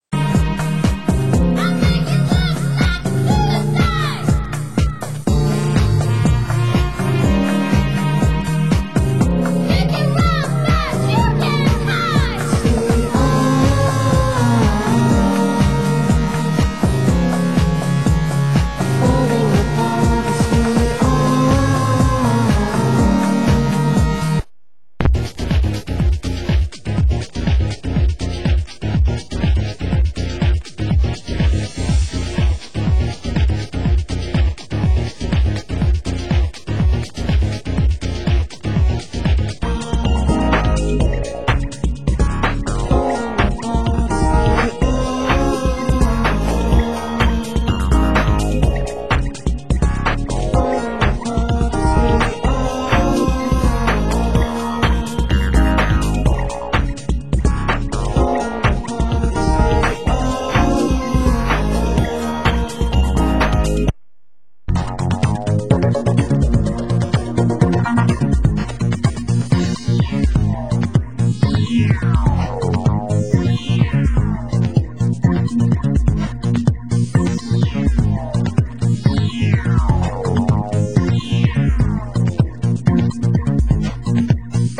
Genre: Electro